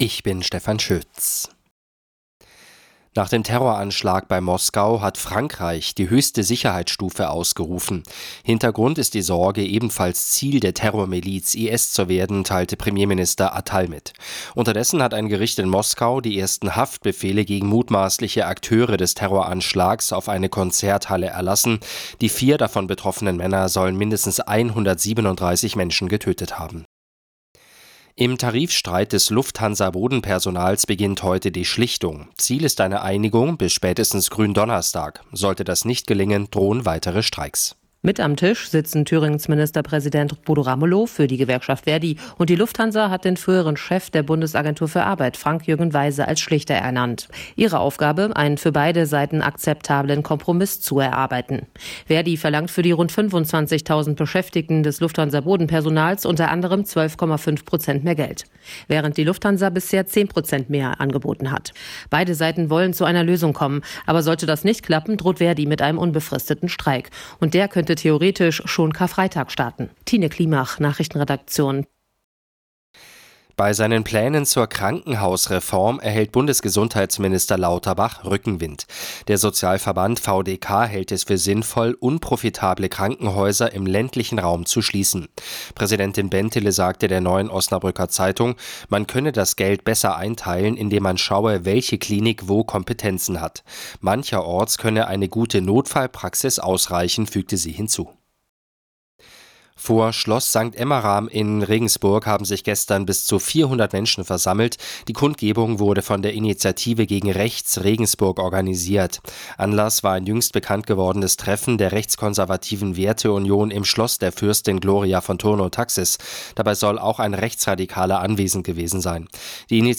Die Arabella Nachrichten vom Montag, 25.03.2024 um 06:36 Uhr - 25.03.2024